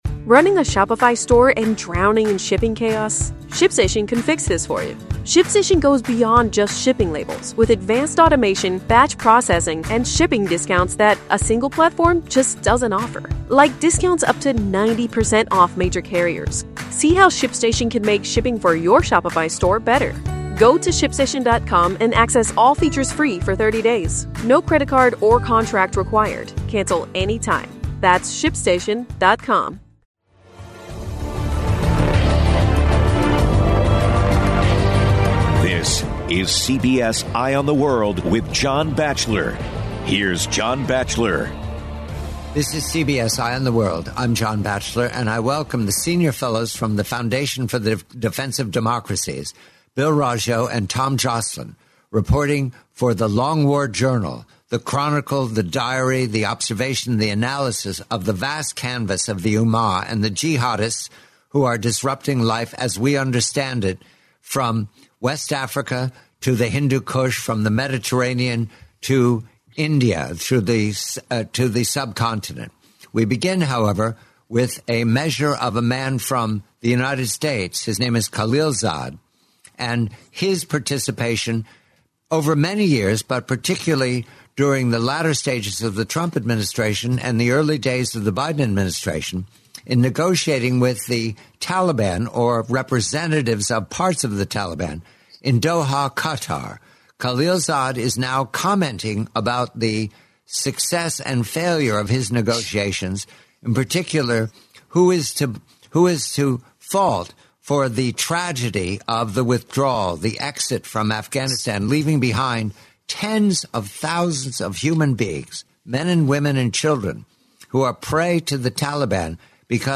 forty-minute interview